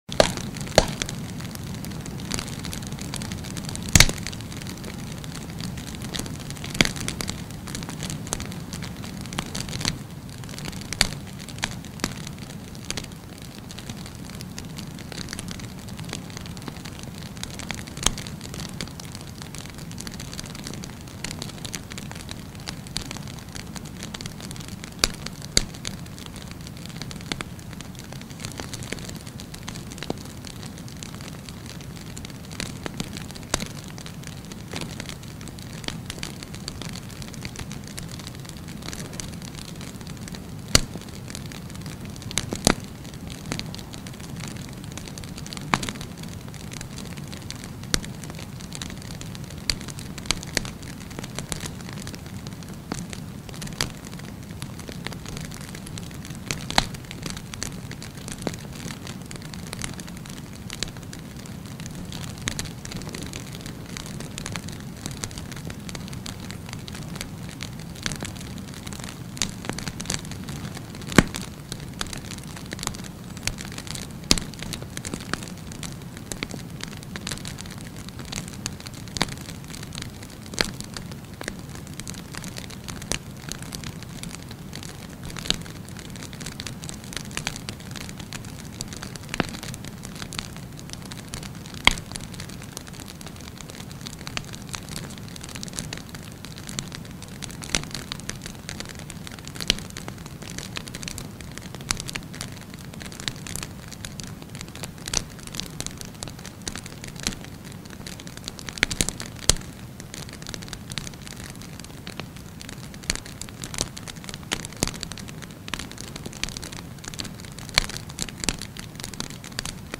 Подборка включает разные варианты: от спокойного потрескивания до яркого горения.
Костер разгорелся, шипение и треск дров